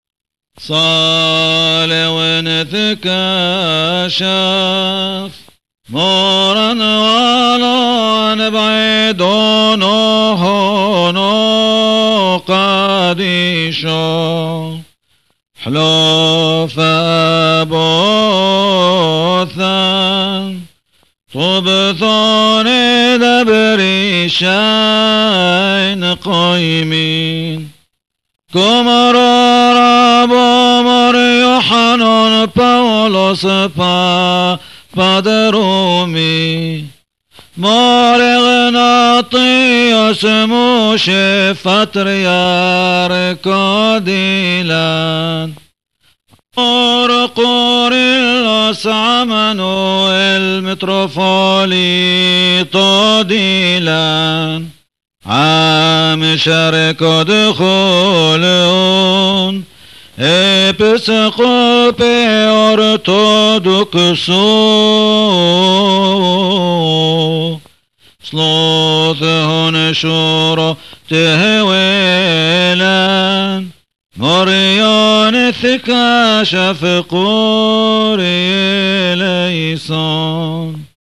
Aramaic Liturgy and Hymns